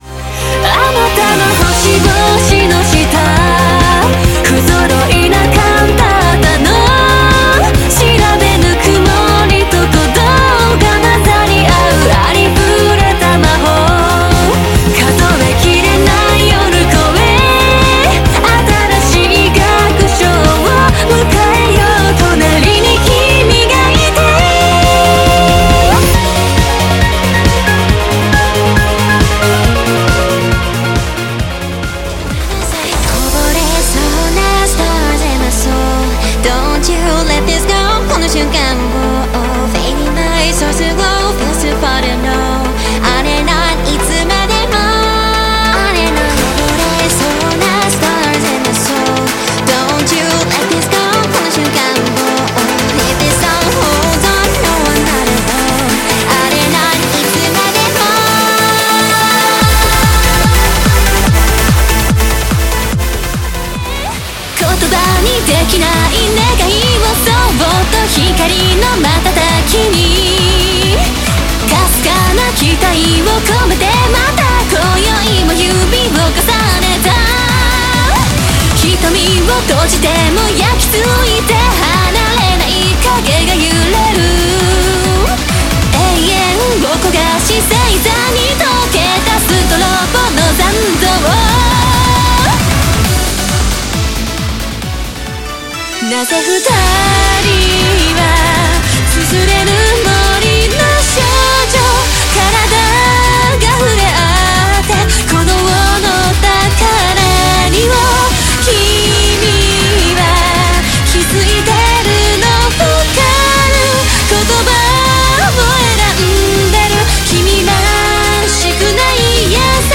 星々の下、君と響き合う優しい音律（メロディ）…